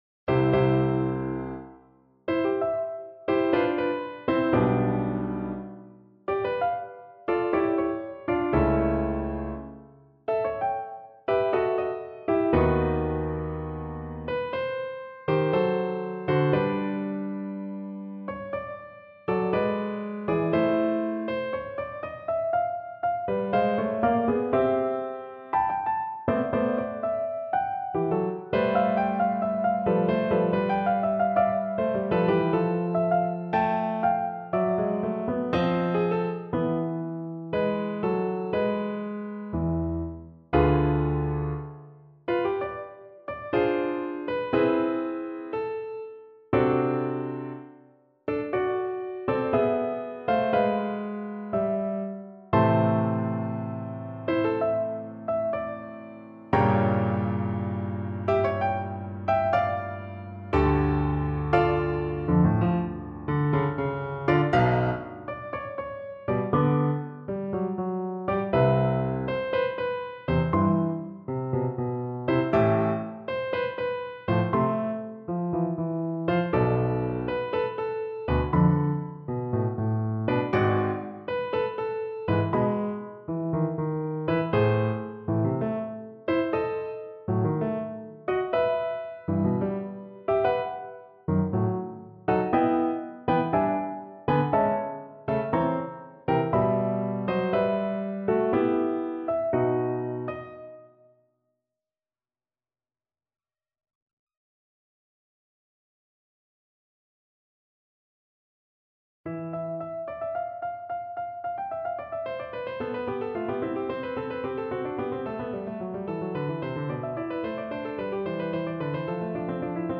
Piano version
Instrument: Piano
Style: Classical